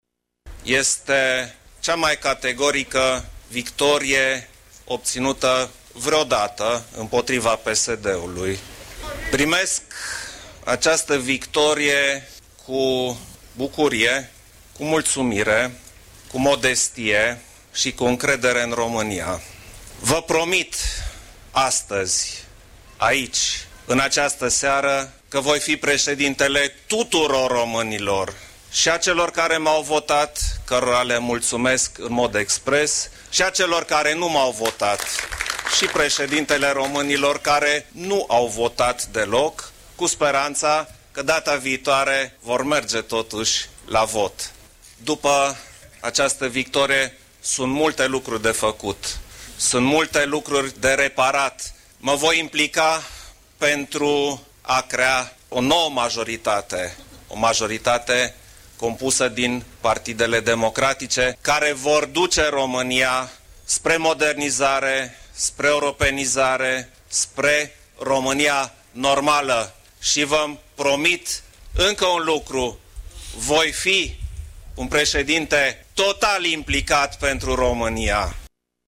Preşedintele Klaus Iohannis a afirmat, duminică seara, după anunţarea rezultatelor exit-poll, că aceasta a fost cea mai categorică victorie obţinută vreodată împotriva Partidului Social Democrat: